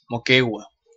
Moquegua (Spanish pronunciation: [moˈkeɣwa]